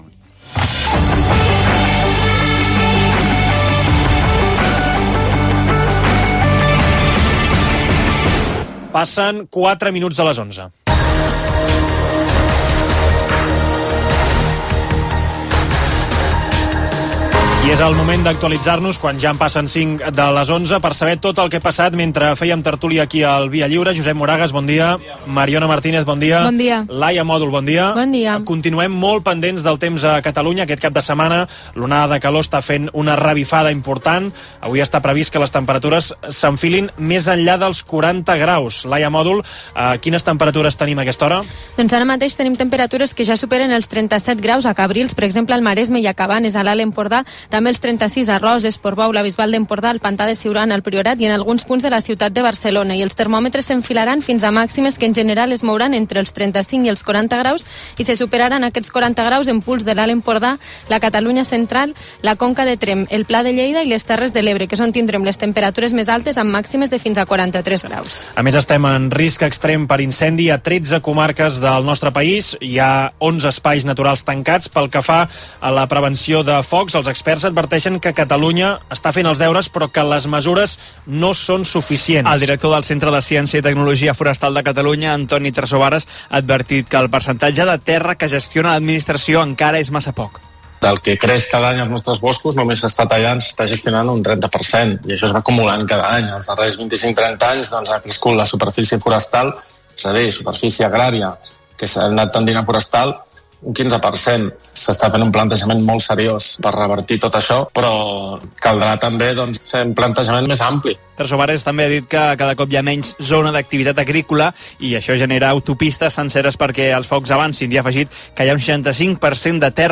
Hora, repàs informatiu (vaga del personal de terra a l'aeroport del Prat, onada de calor, prevenció de focs forestals), hora, sumari de propers continguts, indicatiu del programa, publicitat, indicatiu, hora, espai "Literatura en xancletes" dedicat a mossèn Cinto Verdaguer